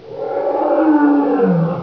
BansheeDying.wav